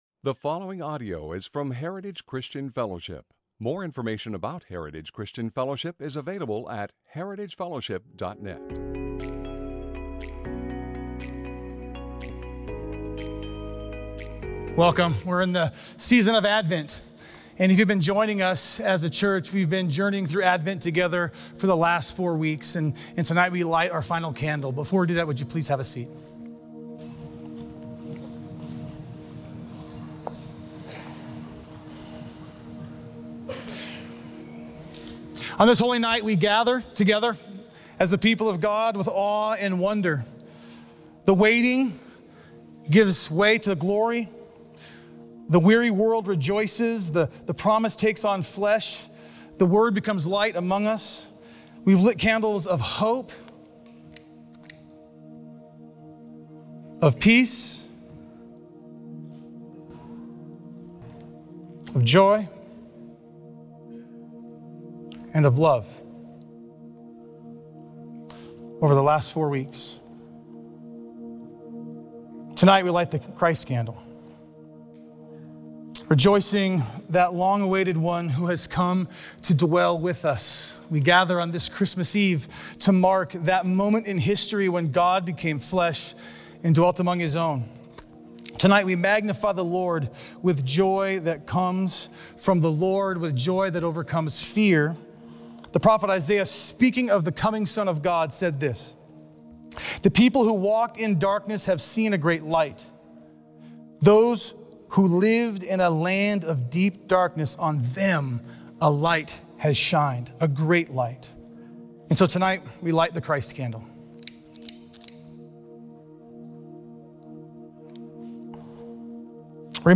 Sort and browse sermons below be Series or by Speaker.